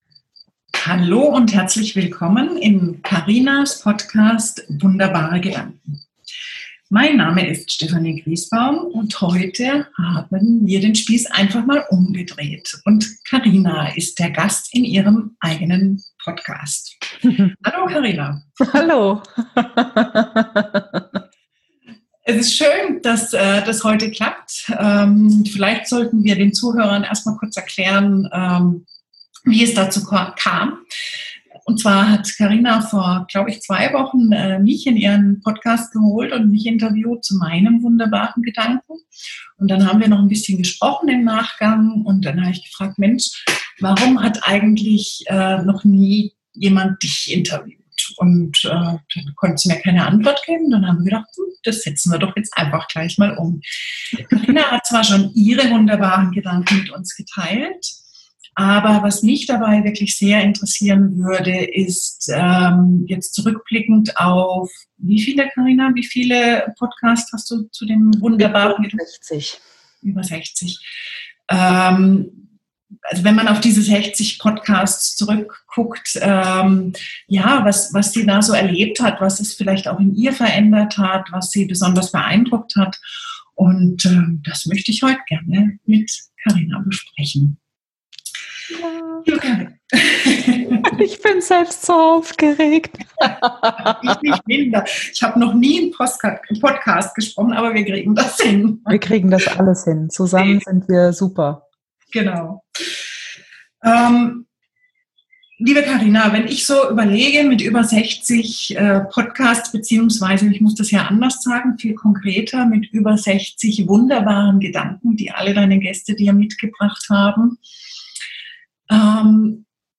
Ein Interview mit mir selbst.